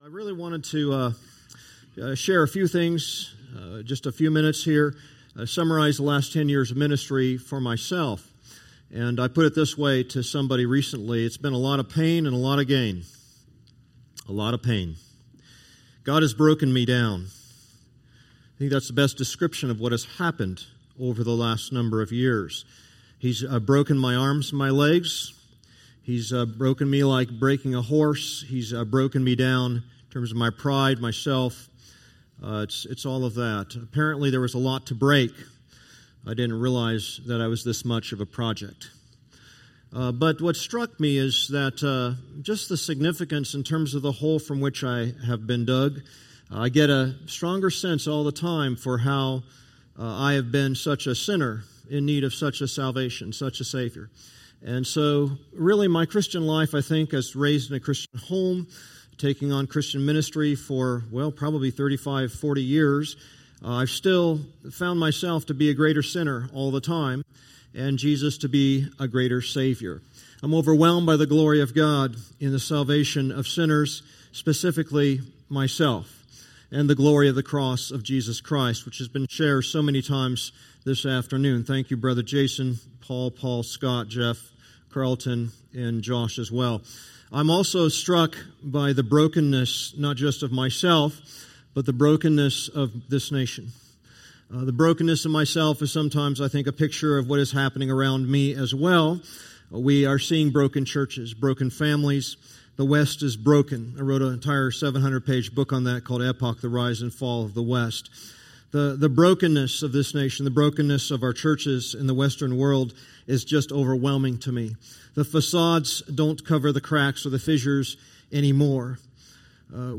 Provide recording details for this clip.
Conference